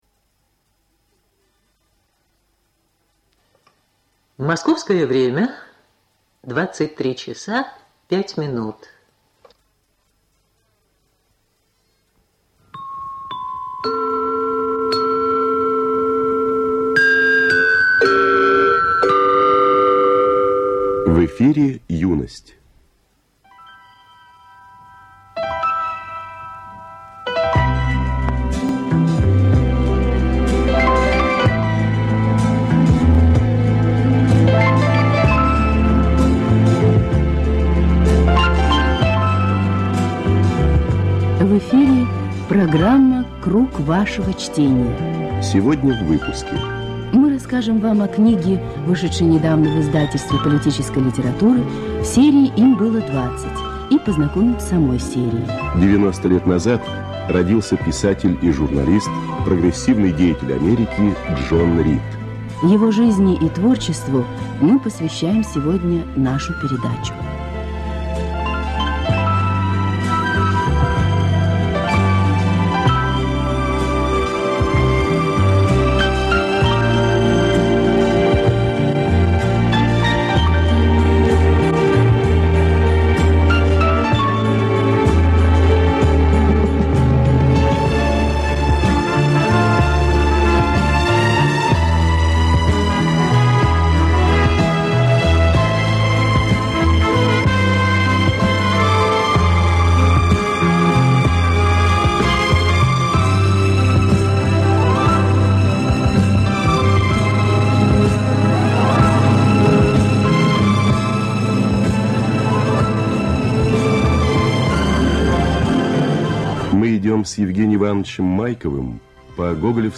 Оцифровка старой ленты.